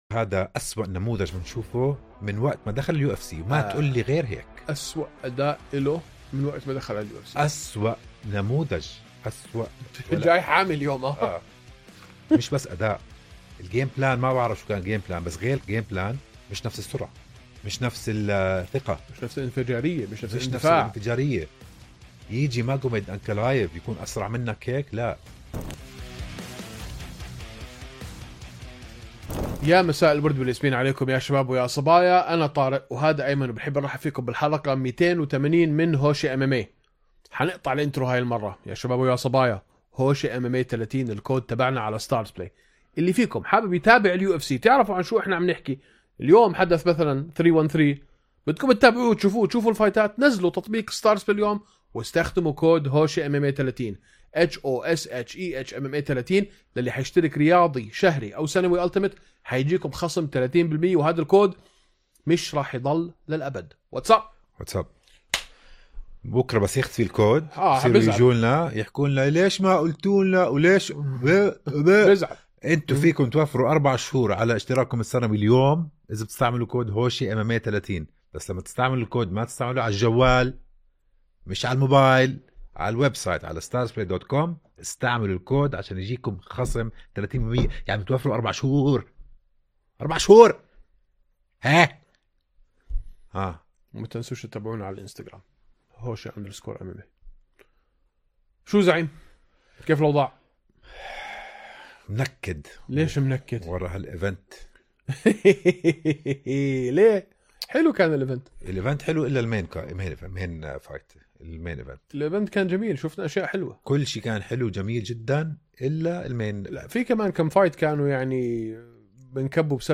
من خلال مناقشات عميقة حول فنون القتال المختلطة، نسعى لتغيير تصور العالم العربي للرياضات القتالية وإعادتها إلى مكانتها في ثقافتنا. تأتيكم حواراتنا بصدق ونقاشات حيوية، مُقدمة من قبل محبين وخبراء في عالم القتال.